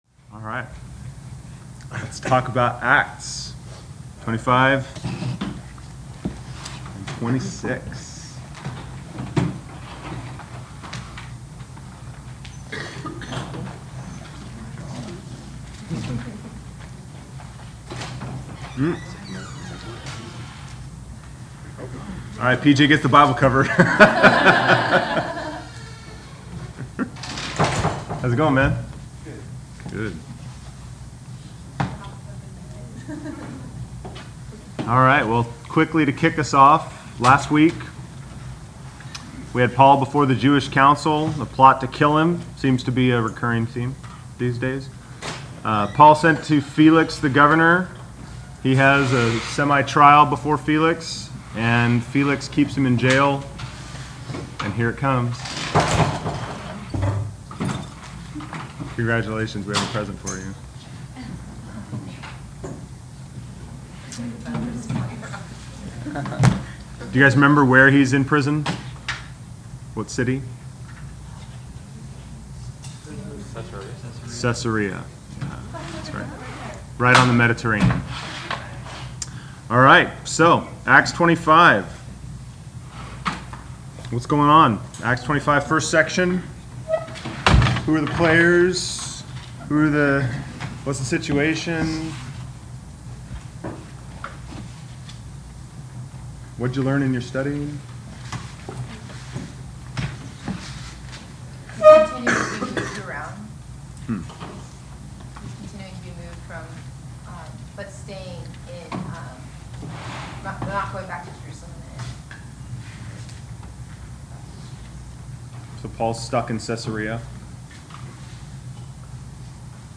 Class Session Audio January 23